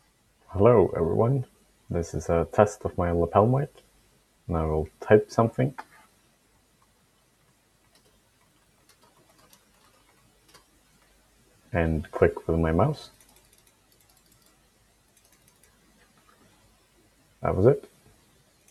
But the only clicks you can really hear in that recording is when I'm slapping the spacebar
lapeltest.mp3